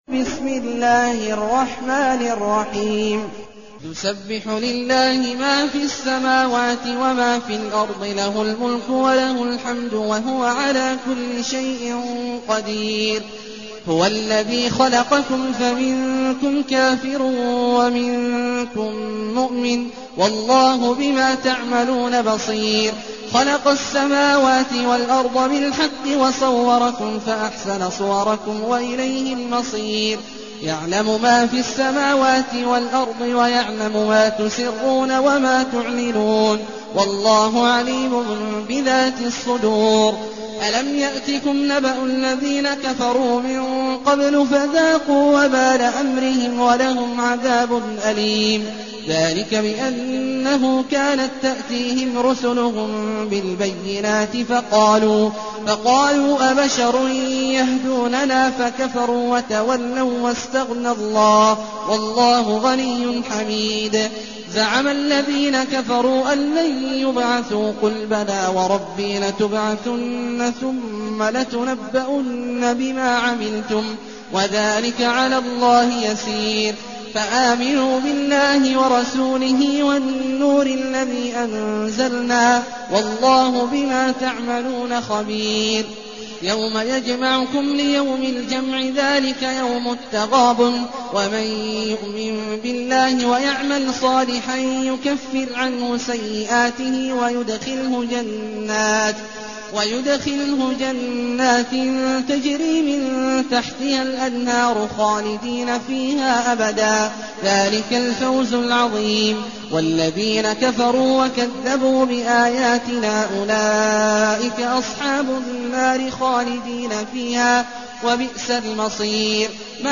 المكان: المسجد النبوي الشيخ: فضيلة الشيخ عبدالله الجهني فضيلة الشيخ عبدالله الجهني التغابن